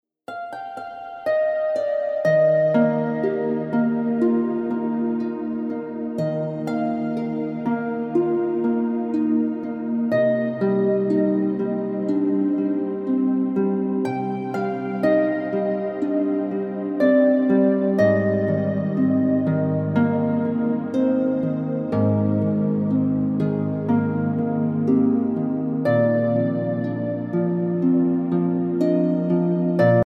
Heavenly Harp Music